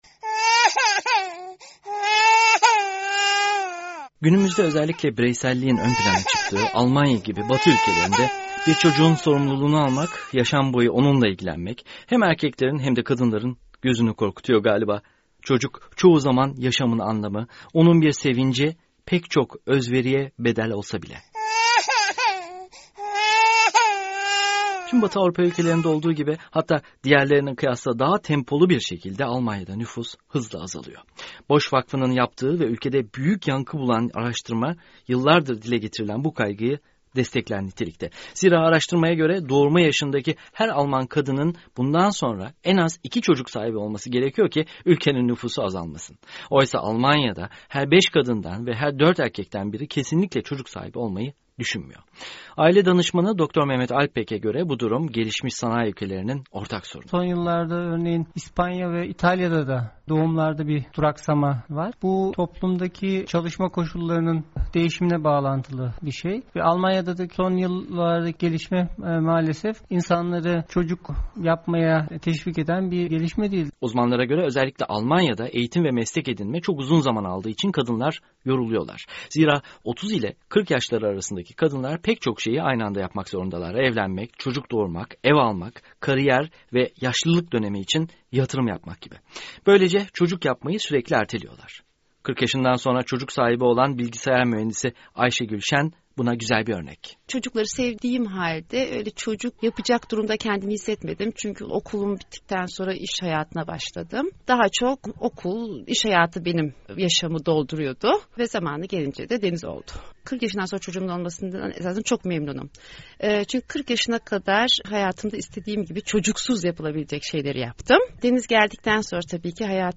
VOA Türkçe - Haberler